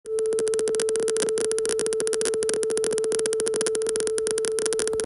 When I tested this setup, I noticed clicking sound artifacts again.
Continuous clicking